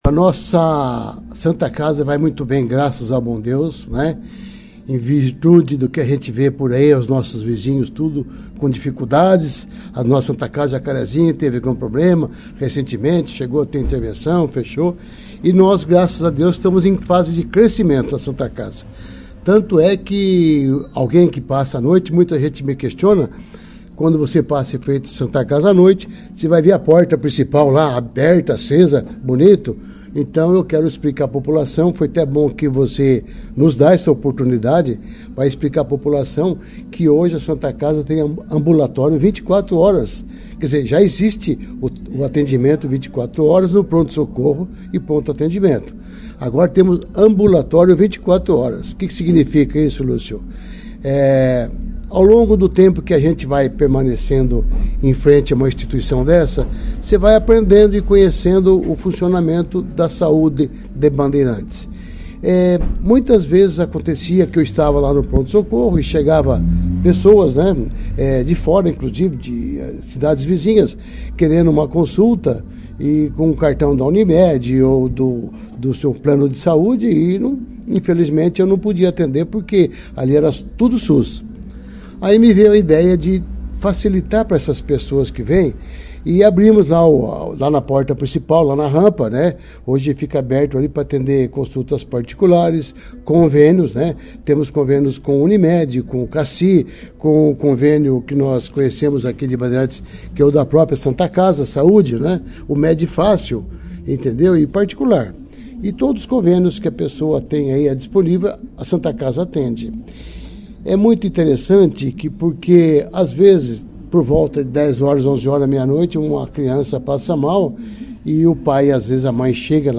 Durante a entrevista, ele enfatizou o trabalho desenvolvido pela entidade no município, que além de garantir o atendimento pelo SUS, também oferece serviços particulares por meio de convênios médicos.